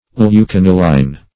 Search Result for " leucaniline" : The Collaborative International Dictionary of English v.0.48: Leucaniline \Leu*can"i*line\ (l[-u]*k[a^]n"[i^]*l[i^]n or -l[=e]n), n. [Leuc- + aniline.]